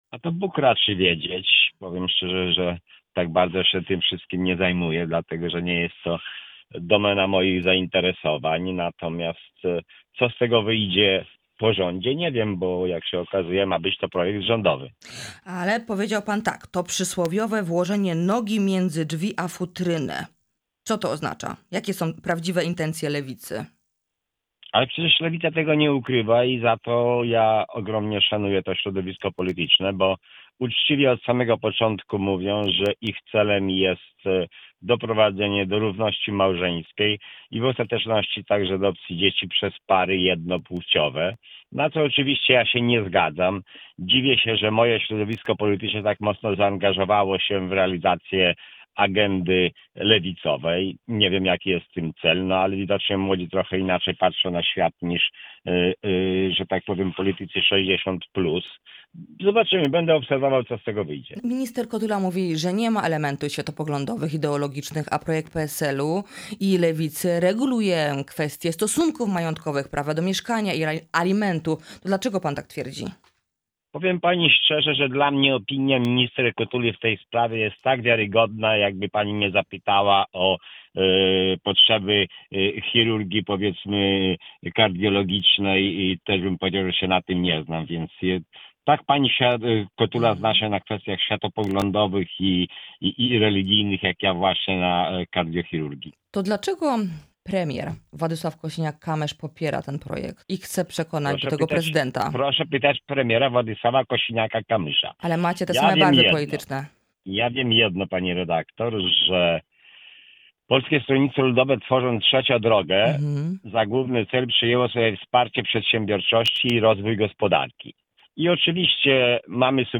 Jaki będzie los ustawy o statusie osoby najbliższej i czy to będzie kolejna kość niezgody w koalicji? – to pytanie zadaliśmy w „Porannym Gościu” marszałkowi seniorowi Markowi Sawickiemu z PSL.